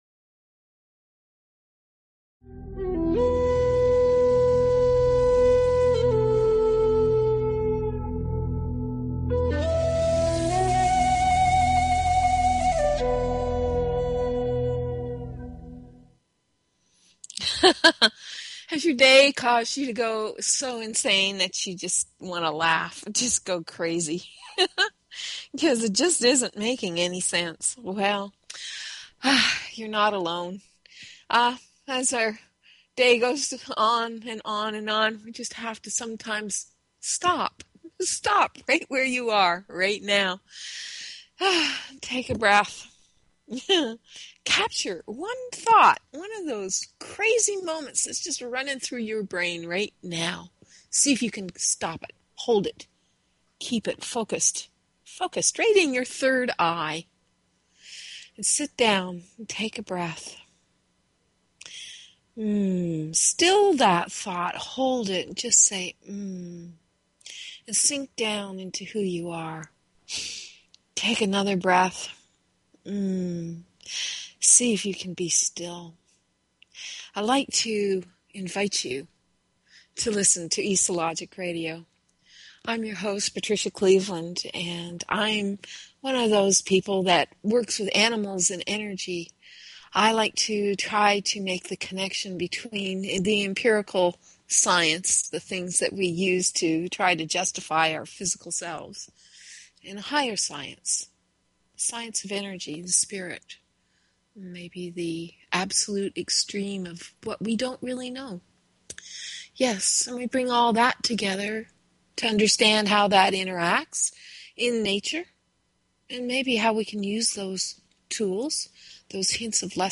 Talk Show Episode, Audio Podcast, eSO_Logic_Radio and Courtesy of BBS Radio on , show guests , about , categorized as